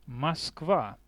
莫斯科（俄语：Москва羅馬化Moskva發音：[mɐsˈkva]
Ru-Москва.oga.mp3